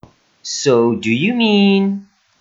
音声もつけていますので、発音をよく聞いて真似してみてください。